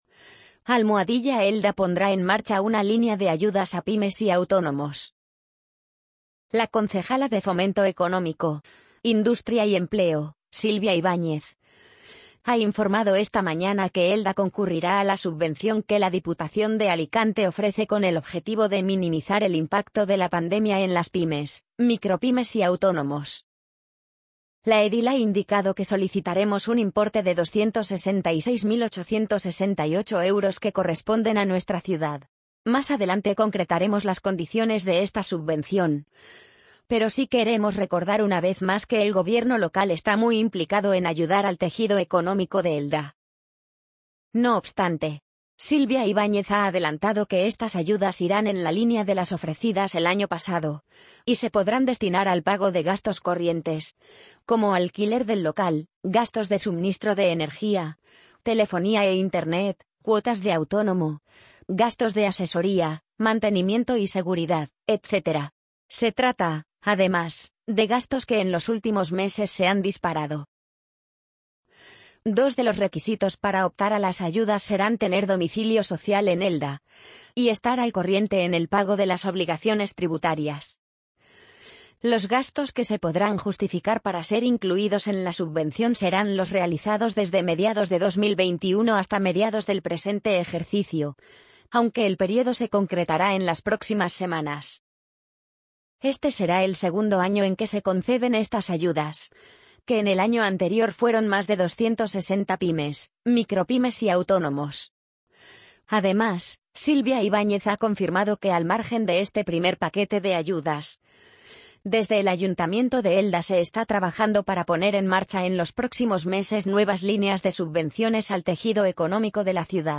amazon_polly_56817.mp3